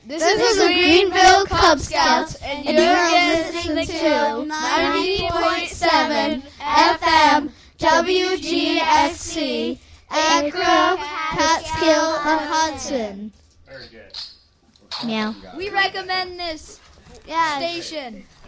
An official WGXC station ID from Greenville Cub Scouts Troop #42 (Audio)